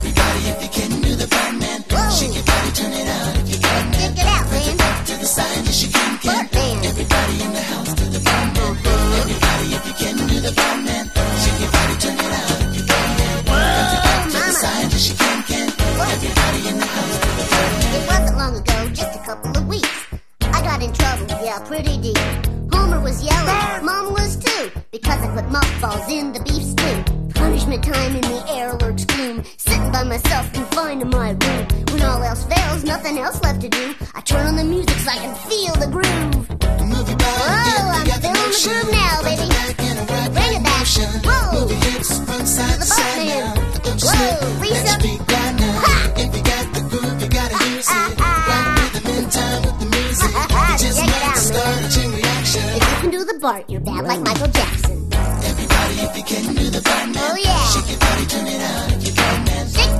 is a funky, high-energy novelty track released in 1990